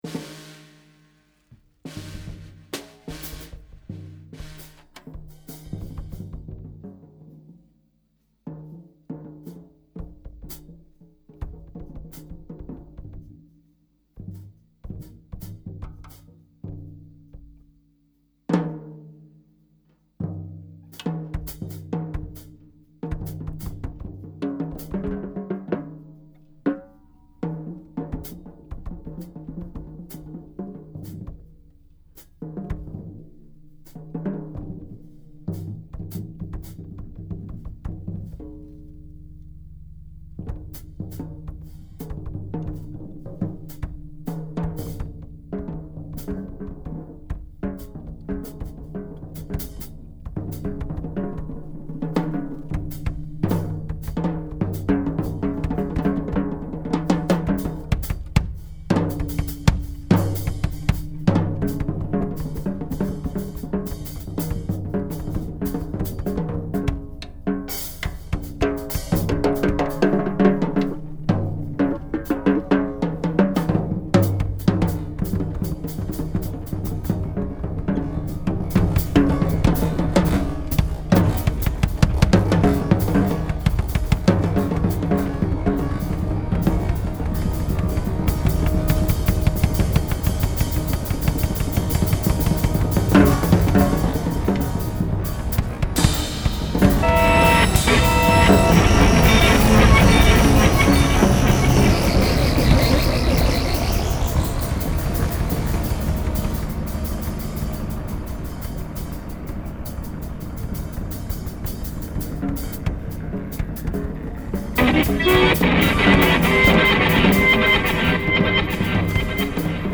Biarritz, le 25 novembre 2016 Main concert